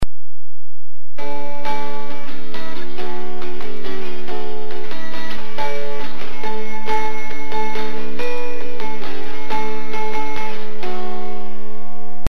The instrument in the photograph is an Appalachian or American dulcimer (pronounced "dull-simmer") instantly recognisable by its traditional "hourglass" shape.
Dulcimer Sound Clips
You can hear the unusual sound produced by the drone strings in the soundclip.